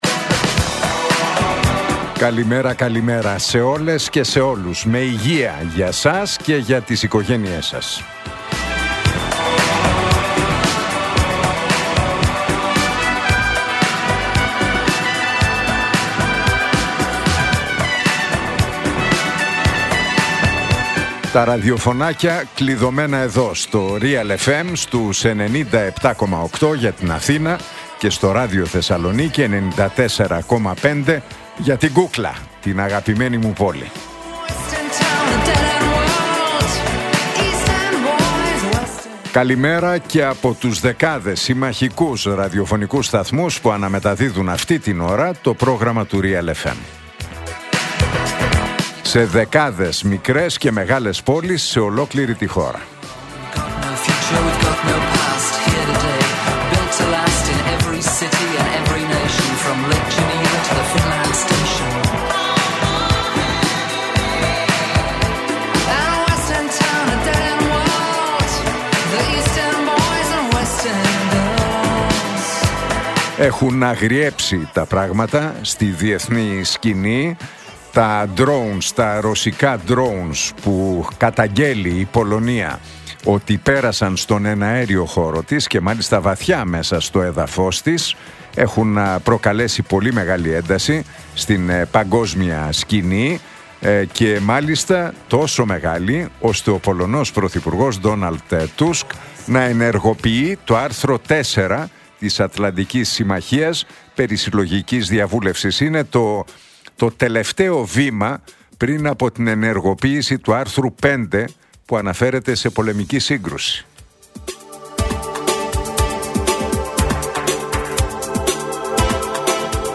Ακούστε το σχόλιο του Νίκου Χατζηνικολάου στον ραδιοφωνικό σταθμό Realfm 97,8, την Πέμπτη 11 Σεπτεμβρίου 2025.